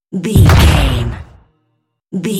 Dramatic hit deep explosion
Sound Effects
heavy
intense
dark
aggressive